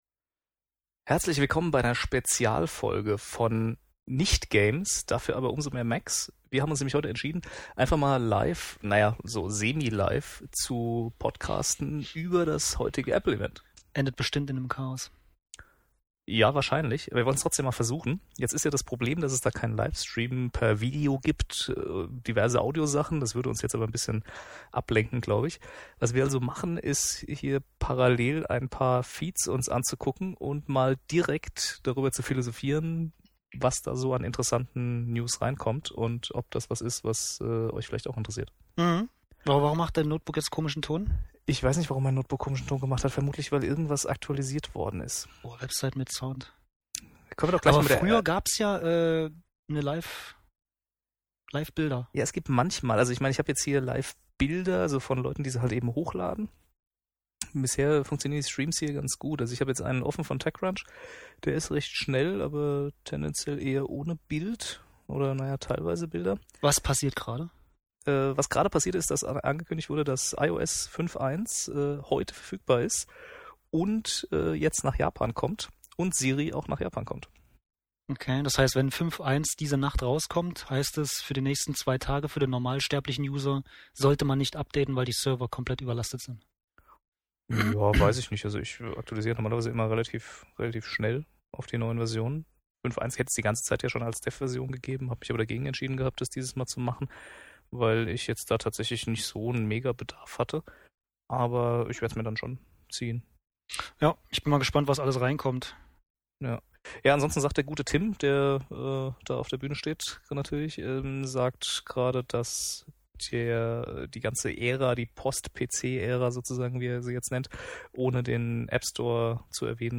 Das Ergebnis ist eine Berichterstattung wie sie sein sollte: spontan, wenig fundiert und mit sinnfreien Einschüben.